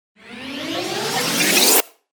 FX-793-RISER
FX-793-RISER.mp3